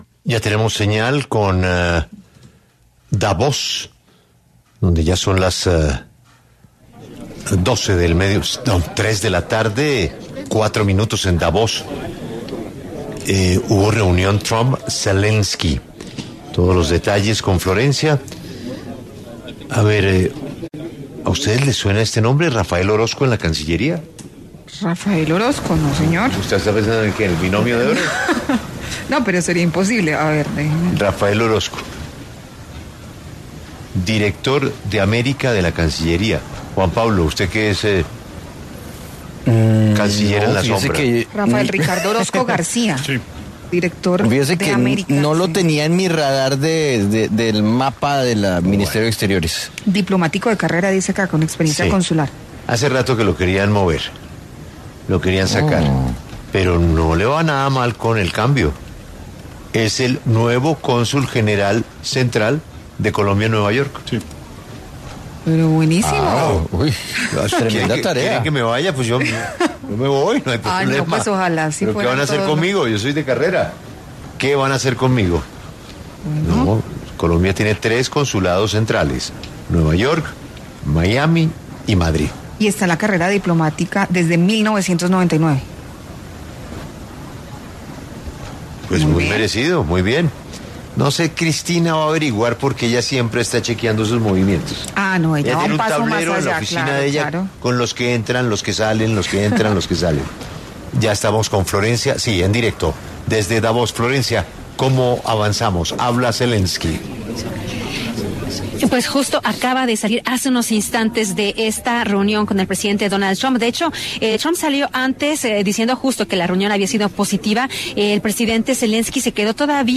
Caracol Radio se encuentra desde el Foro Económico Mundial, en Davos Suiza, y allí consultó con algunos líderes mundiales sobre su perspectiva y lo que esperan de la creación de la Junta de Paz, impulsada por el presidente de Estados Unidos, Donald Trump.
Dentro de las voces consultadas se habló con Ilham Alíyev, político azerí y cuarto y actual presidente de la República de Azerbaiyán desde 2003; Vjosa Osmani-Sadriu, jurista kosovar, actual presidenta de Kosovo; y Ebba Elisabeth Busch-Christensen, viceprimera ministra de Suecia.